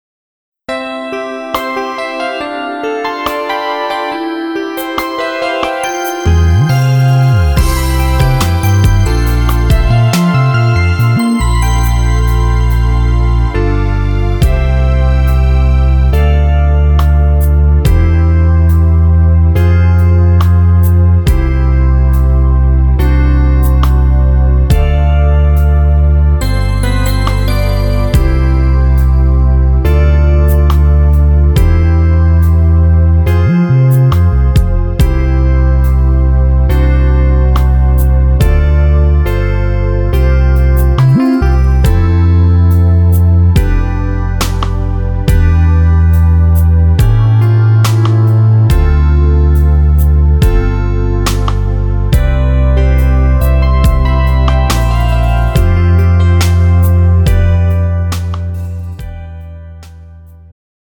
음정 여자키
장르 축가 구분 Pro MR